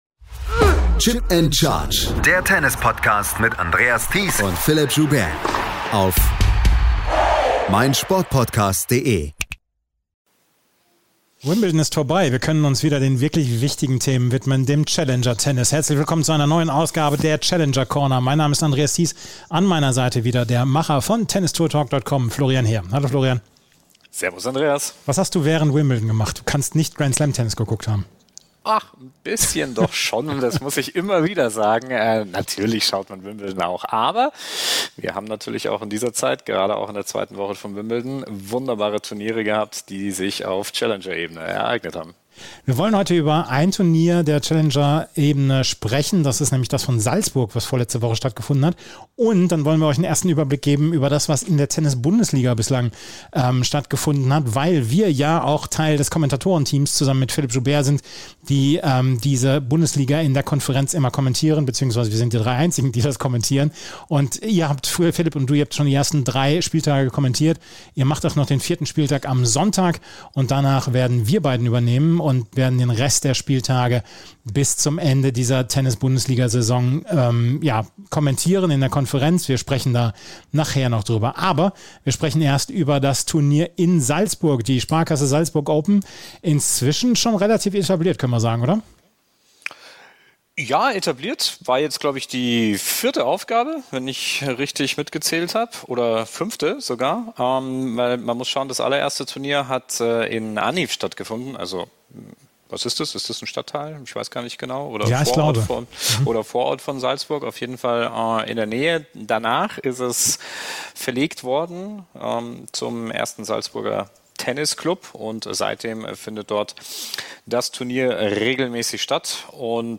Wir waren in Salzburg vor Ort und haben uns mit dem Linkshänder zum Gespräch verabredet.